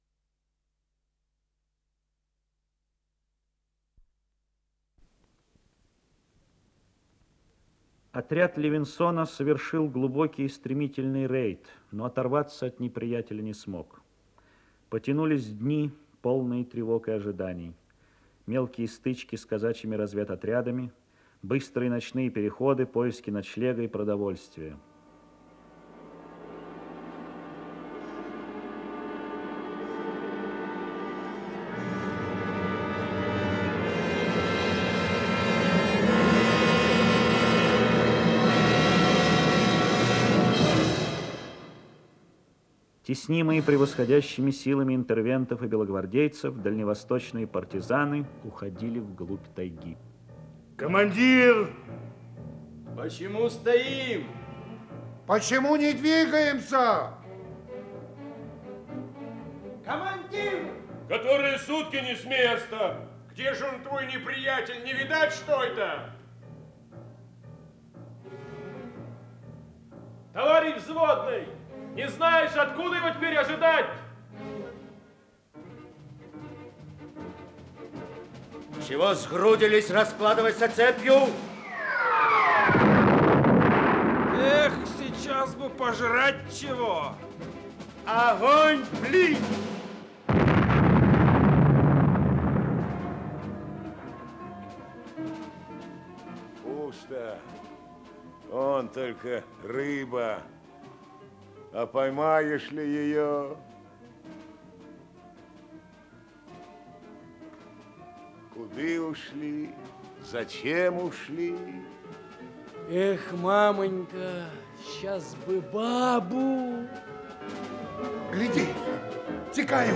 Исполнитель: Артисты театра им. В. Маяковского
Радиоспектакль